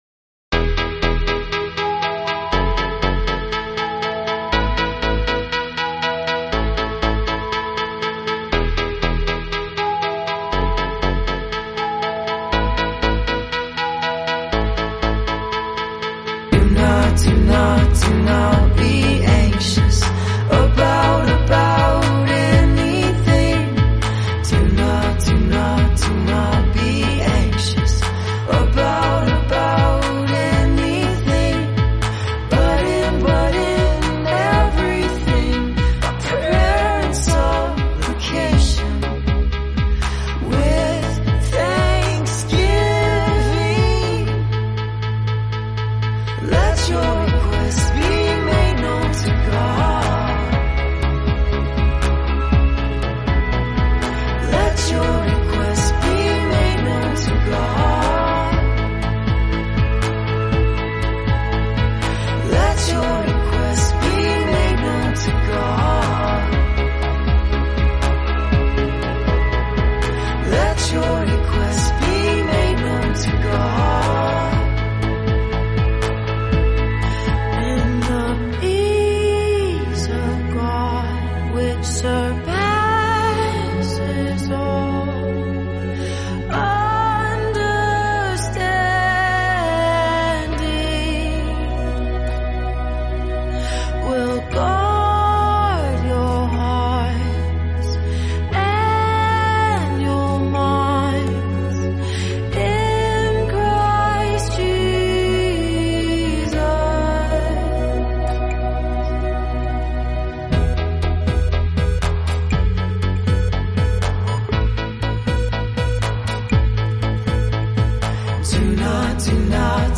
word-for-word Scripture song
as a melodic anchor